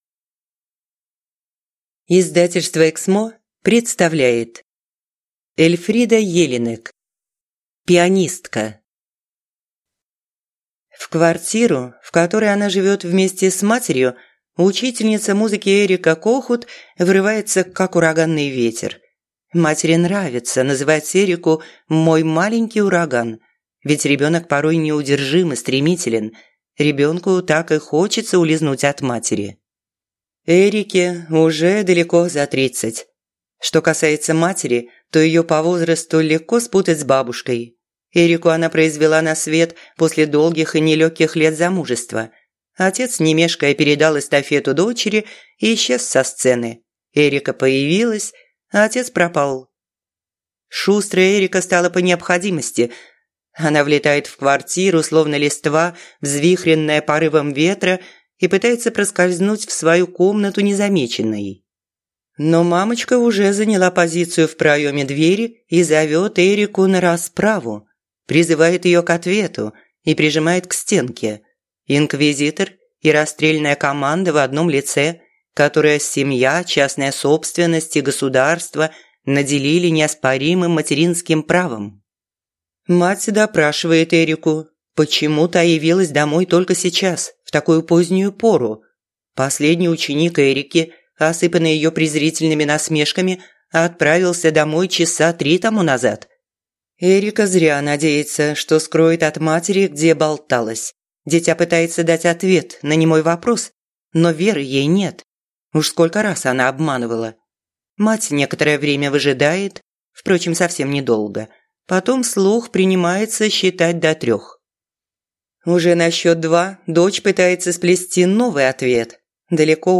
Аудиокнига Пианистка | Библиотека аудиокниг